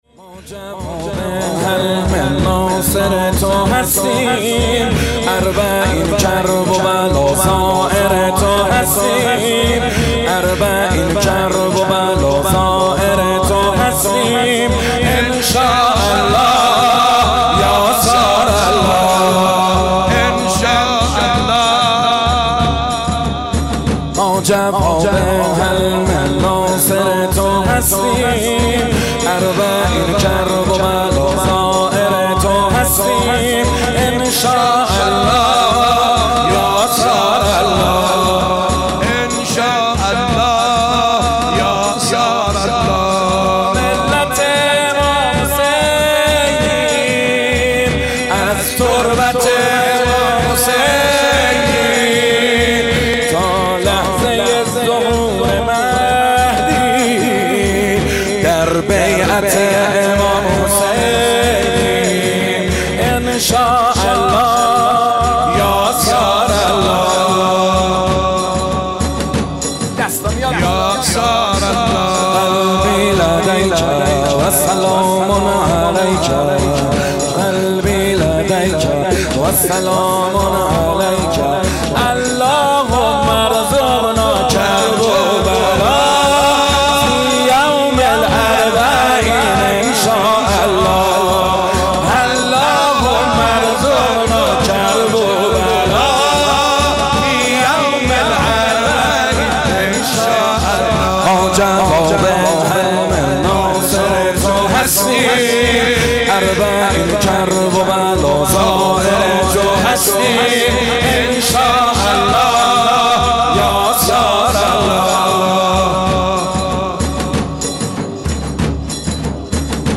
صوت مداحی
به گزارش خبرنگار فرهنگی خبرگزاری تسنیم، مراسم عزاداری دهه اول محرم در مهدیه امام حسن مجتبی (ع) هر روز صبح از ساعت 6 صبح آغاز می‌شود.
روضه زمینه ای برادر شهیدم راهتو ادامه میدم